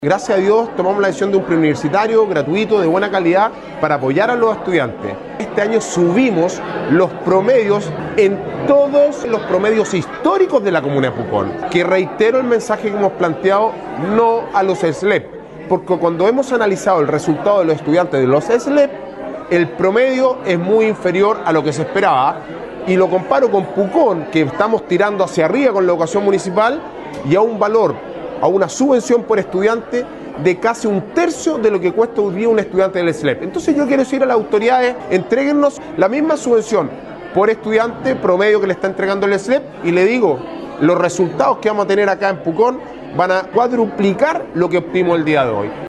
Alcalde-Sebastian-Alvarez-valora-el-aporte-municipal-y-confirma-su-NO-al-SLEP.mp3